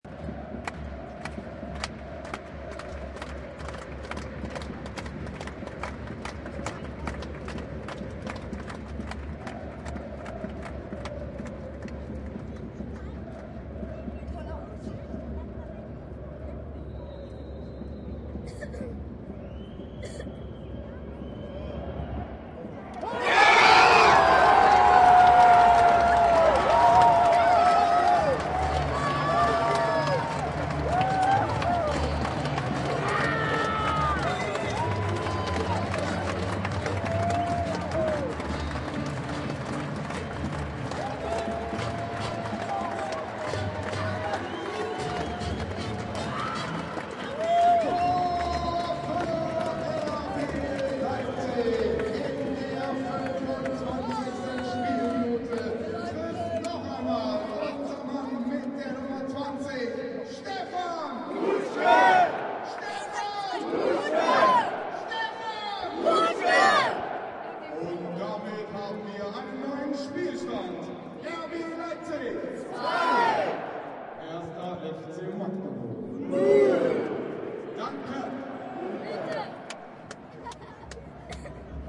Download Free Goal Sound Effects
Goal